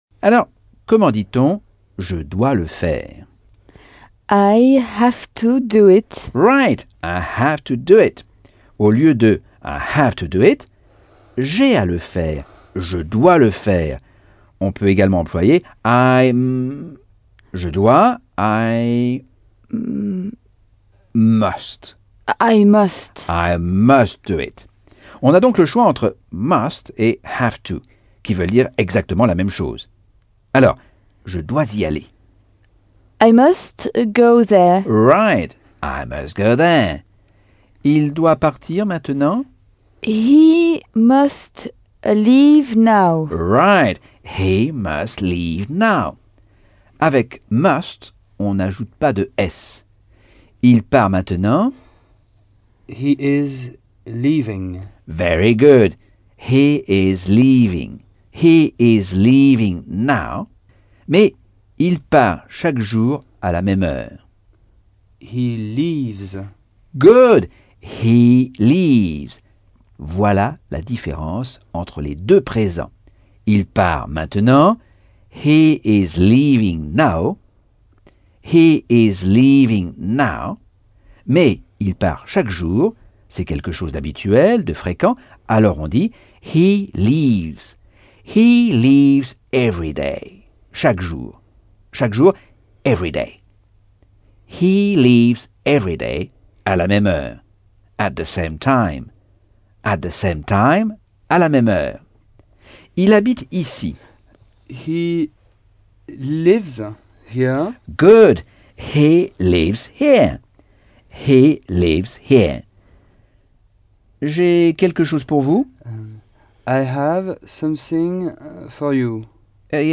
Leçon 11 - Cours audio Anglais par Michel Thomas